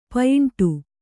♪ payiṇṭu